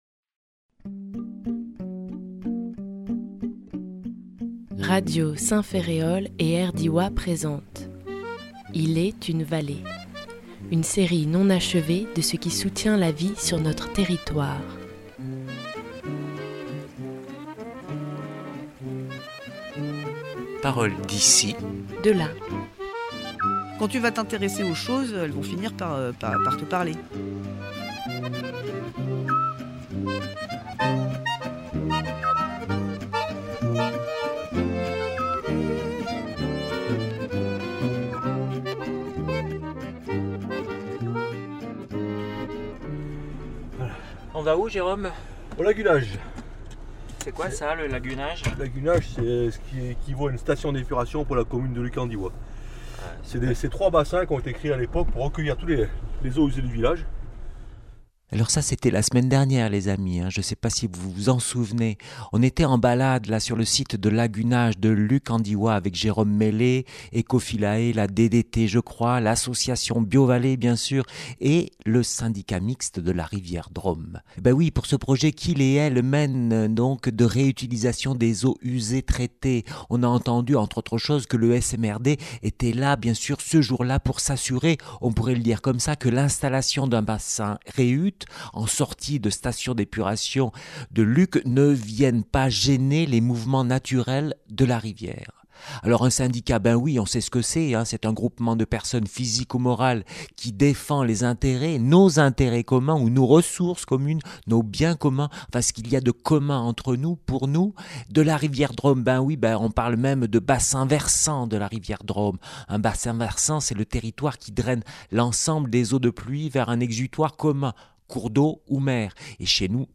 Et bien, c’est ce que vous allez entendre si vous prenez le temps de vous laisser glisser dans le courant léger de cette réalisation radiophonique. En même temps que vous ferez connaissance avec le Syndicat Mixte de la Rivière Drôme , vous découvrirez un peu de l’histoire de la rivière Drôme.